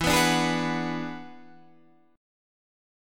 E9 Chord
Listen to E9 strummed